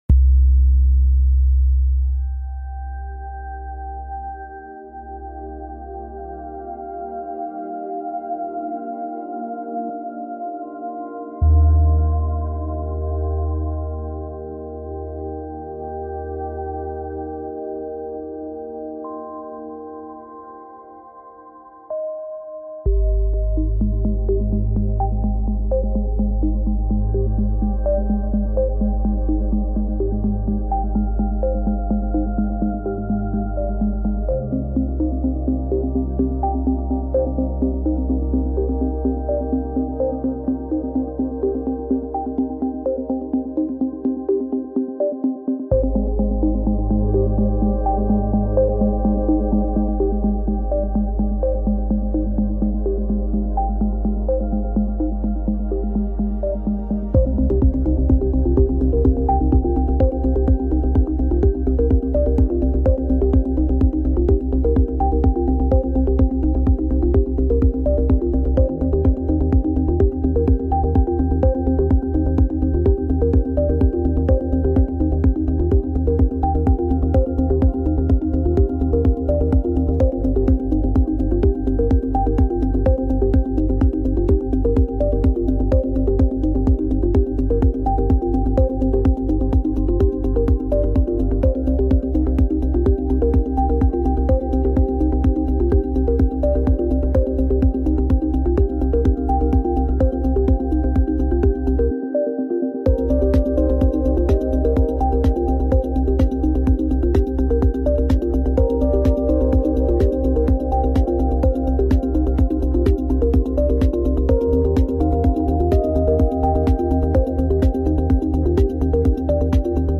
Digital-Focus-–-Calm-Ambient-Work-Music-KpCM5iXWqvk.mp3